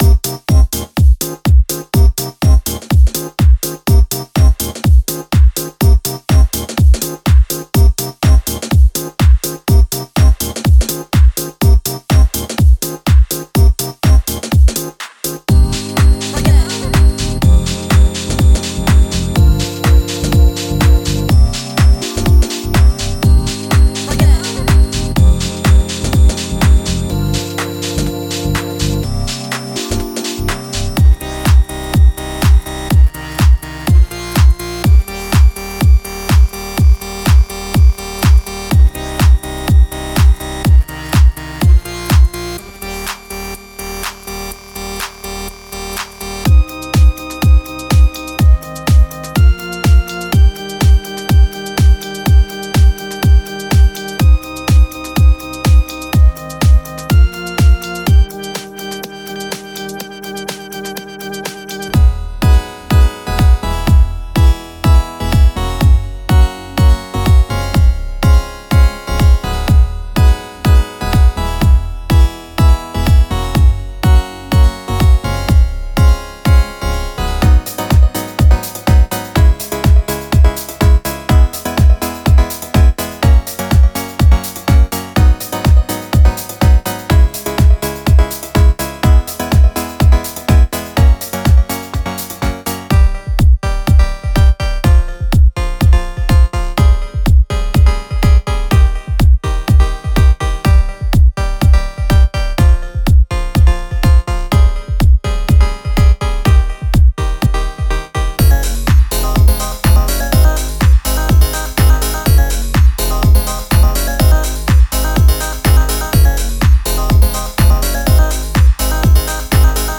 Synth Presets
bass prestes,chords,pads,drum presets